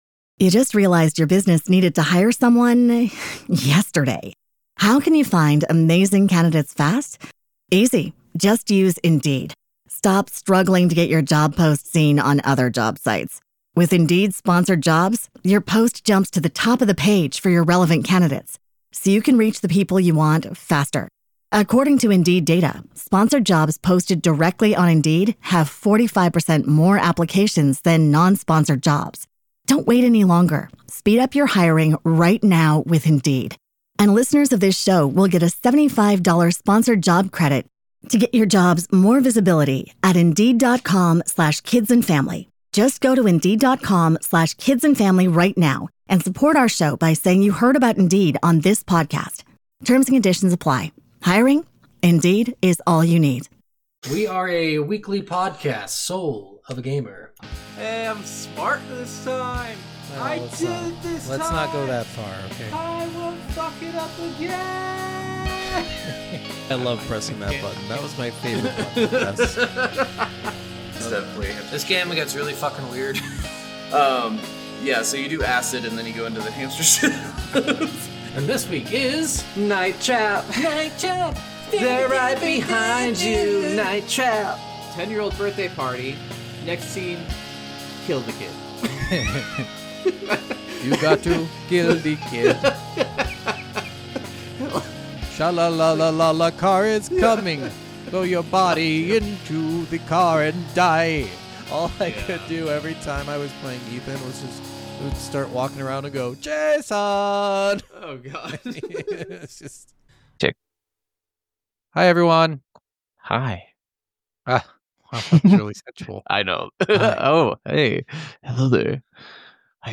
Soul of a Gamer Podcast is a game review podcast from the minds of two average gamers who work full time jobs and cuss a lot.